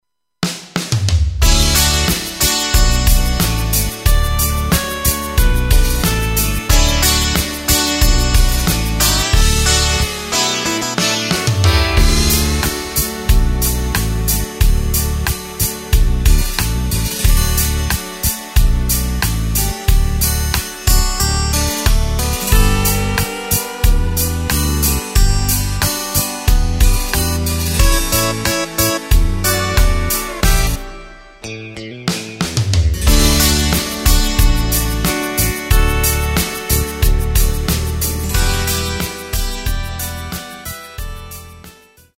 Takt:          4/4
Tempo:         91.00
Tonart:            D
Country Song aus dem Jahr 2025!
Playback mp3 Demo